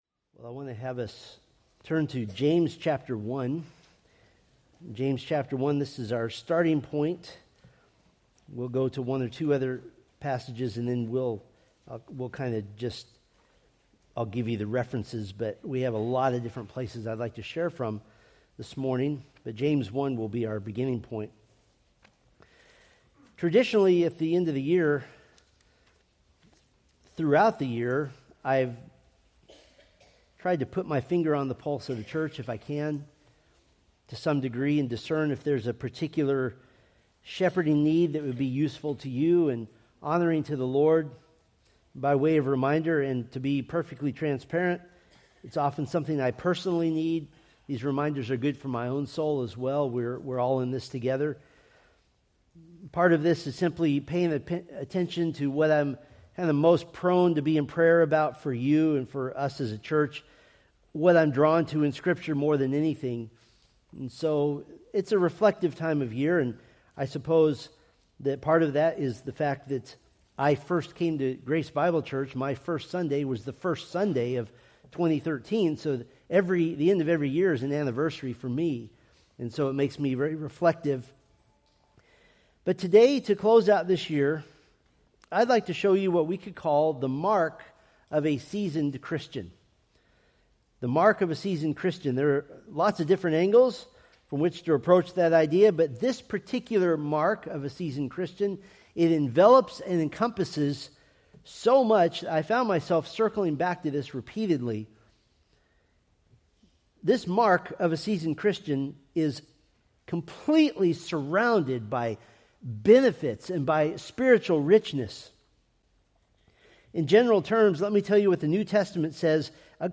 Preached December 28, 2025 from Selected Scriptures